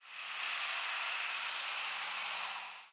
Descending seeps
Saltmarsh Sharp-tailed Sparrow
The "descending seep" group is a large species complex in eastern North America whose flight calls are above 6KHz in frequency and are descending in pitch.